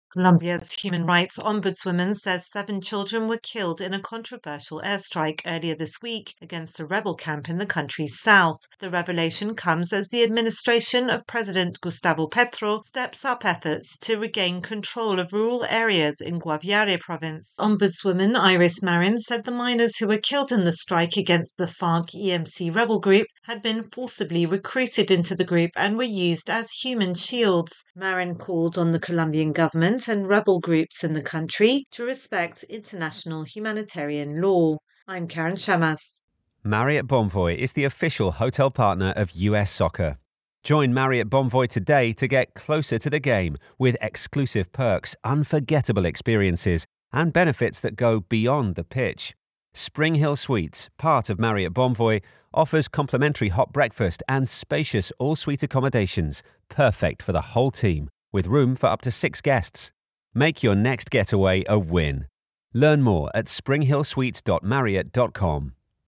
reports on an airstrike in Colombia that killed several children.